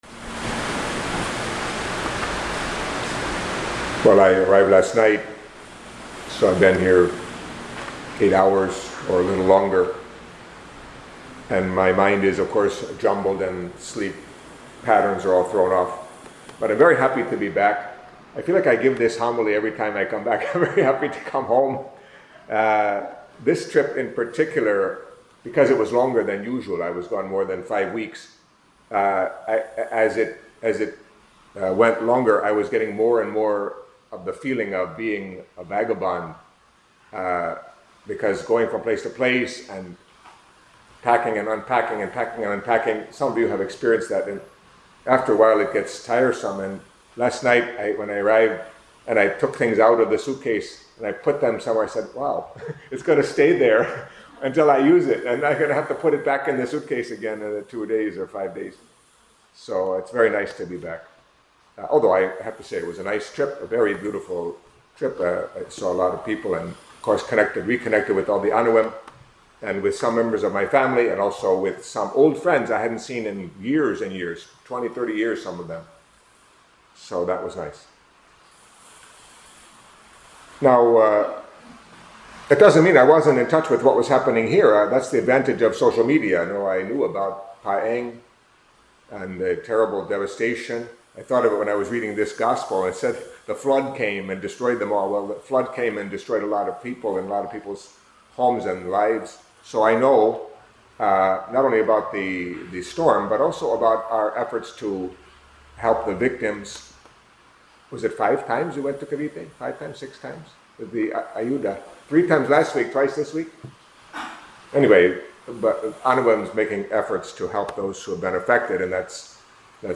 Catholic Mass homily for Friday of the Thirty-Second Week of Ordinary Time